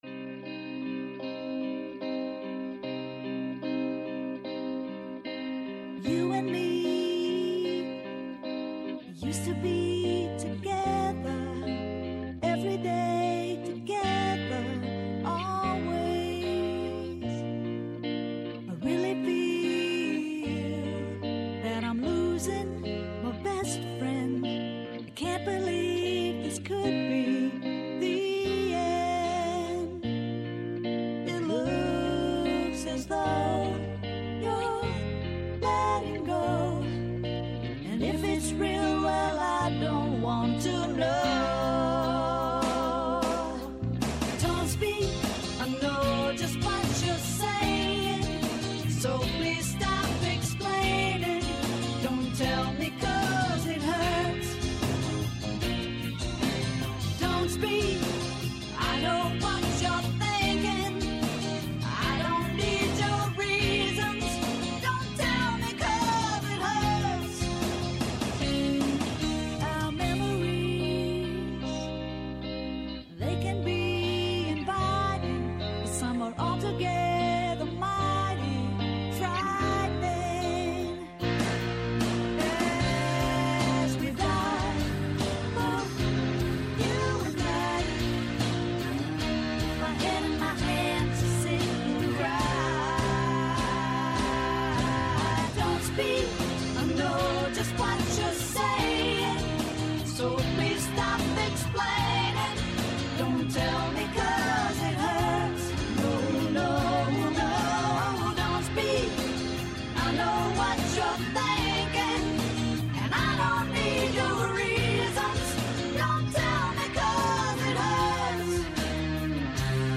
Καλεσμένοι ο ηθοποιός Τάσος Χαλκιάς για τον θάνατο του παραγωγού κινηματογραφικών ταινιών Γιώργου Καραγιάννη